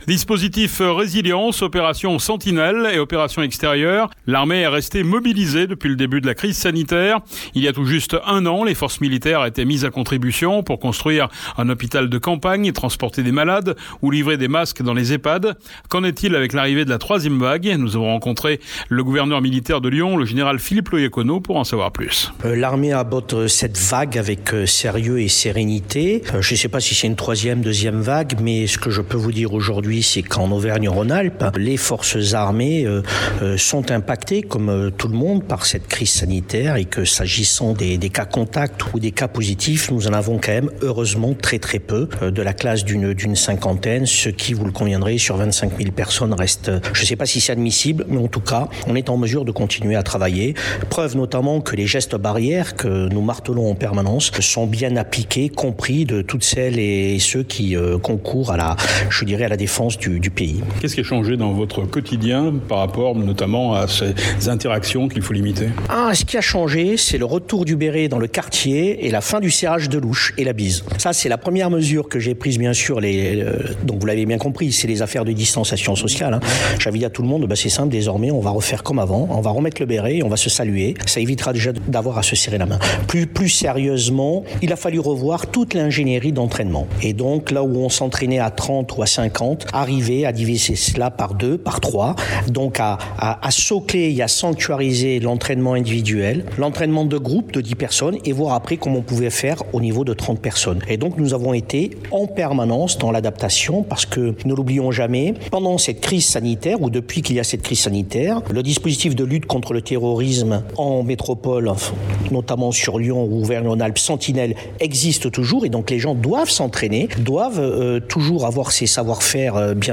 Qu’en est-il avec l’arrivée de la troisième vague ? Nous avons rencontré le Gouverneur Militaire de Lyon, le Général Philippe Loiacono pour en savoir plus